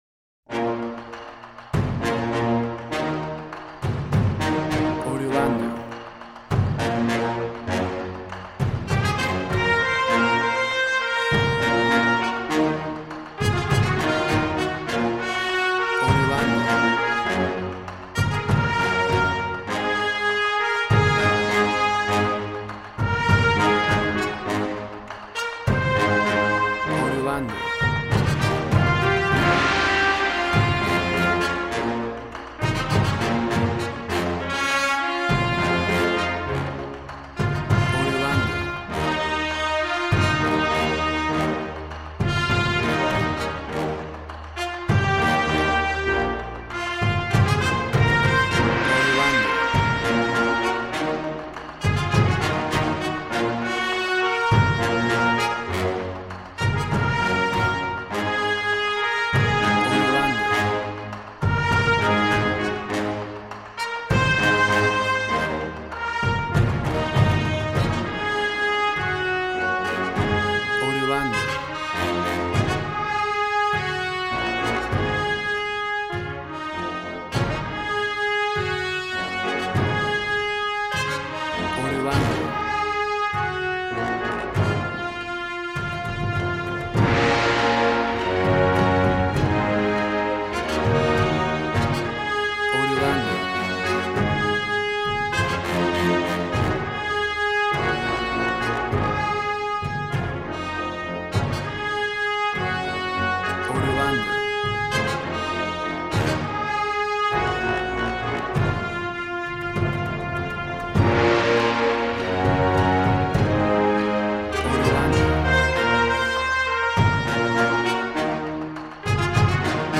Action and Fantasy music for an epic dramatic world!
Tempo (BPM): 100